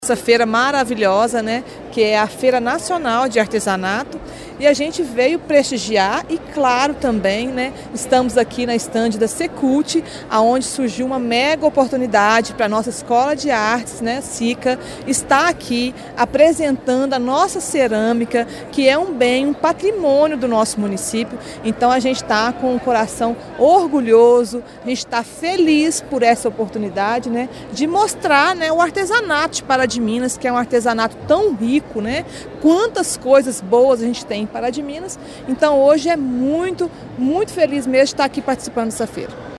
A secretária municipal de Cultura e Comunicação Institucional, Andréia Xavier Paulino, destaca que Pará de Minas foi convidada pela Secretaria de Estado de Cultura e Turismo de Minas Gerais para participar da Feira Nacional do Artesanato.